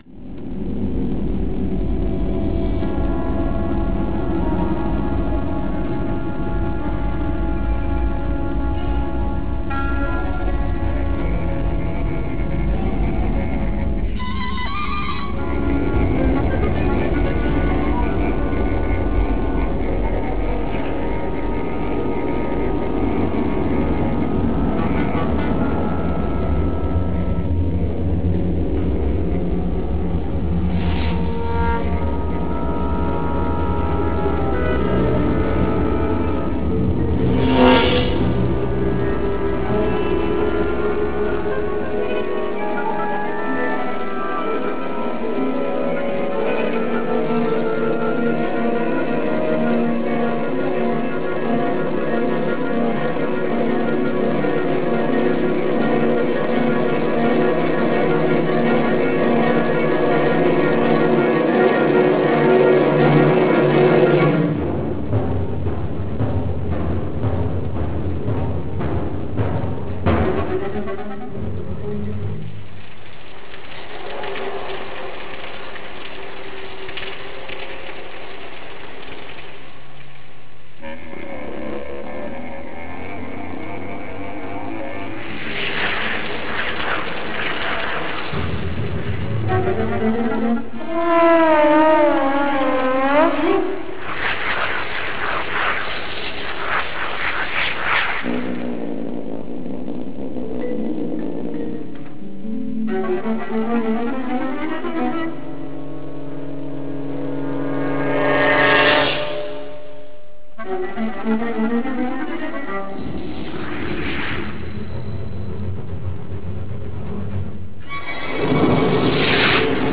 for orchestra and tape 2x2 tracks